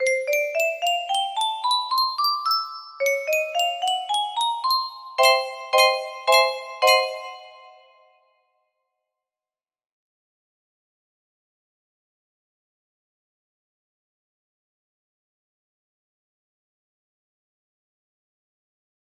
basic equipment test music box melody